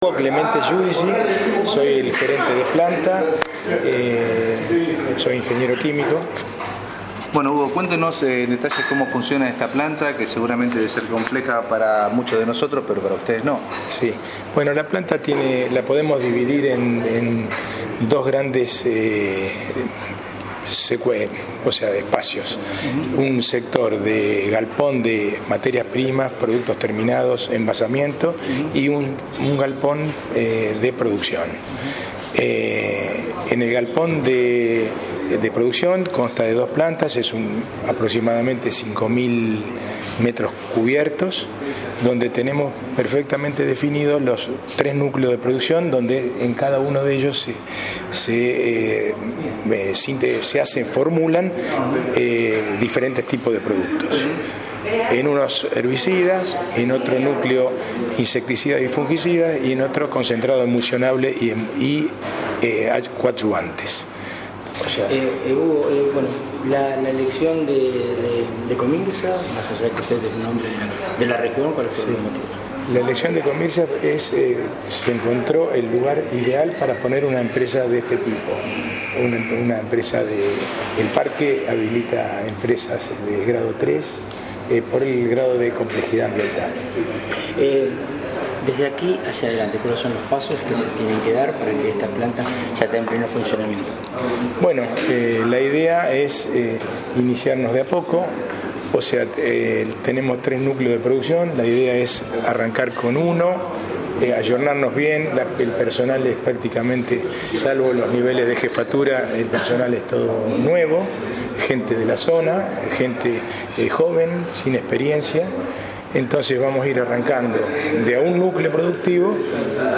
RUEDA DE PRENSA PREVIA CON PERIODISTAS INVITADOS.